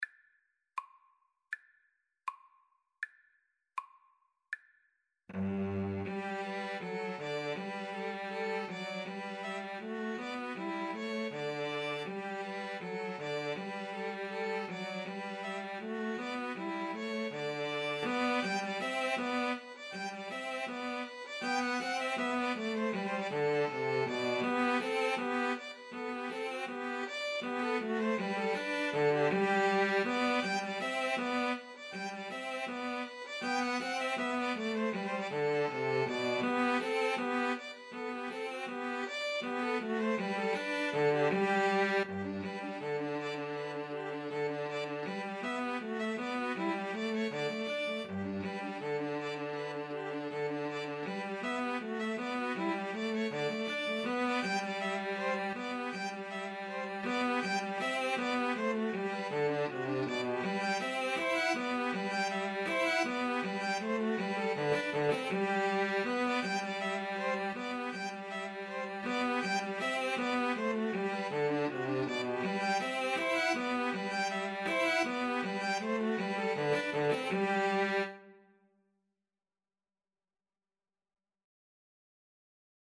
G major (Sounding Pitch) (View more G major Music for 2-Violins-Cello )
Andante cantabile
Classical (View more Classical 2-Violins-Cello Music)